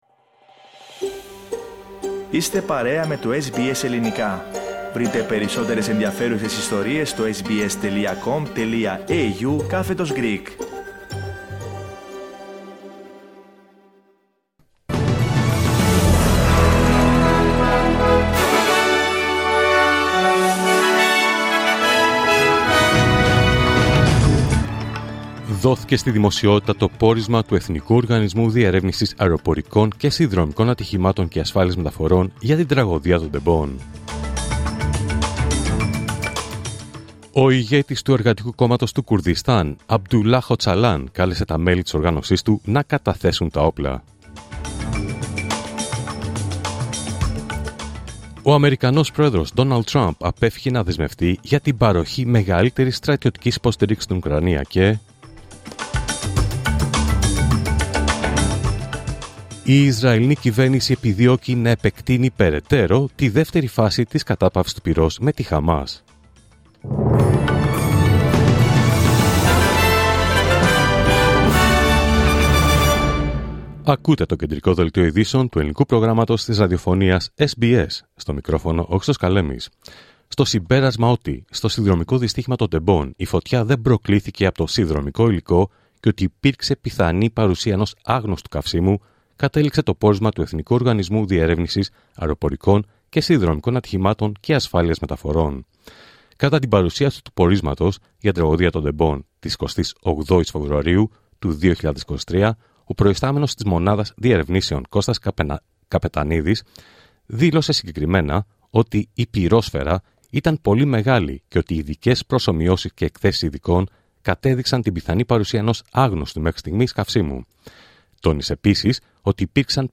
Δελτίο Ειδήσεων Παρασκευή 28 Φεβρουάριου 2025